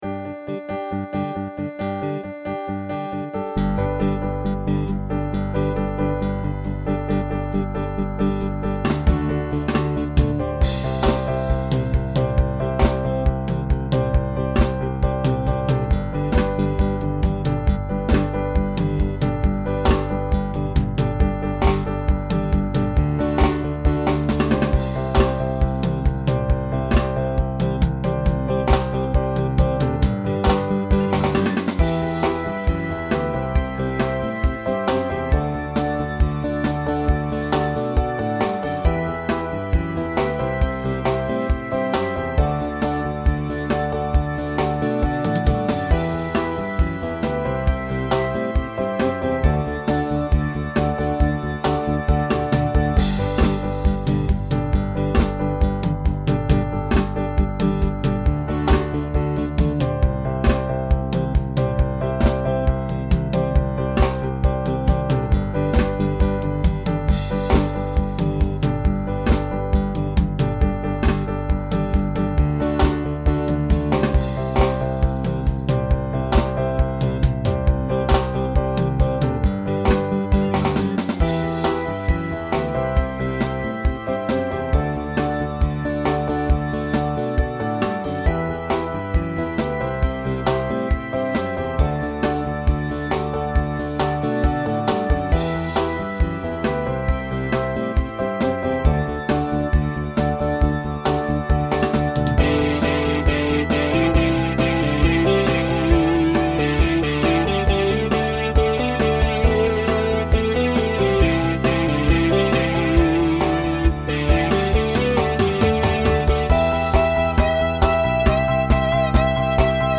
guys|girls feature
Voicing SATB Instrumental combo Genre Rock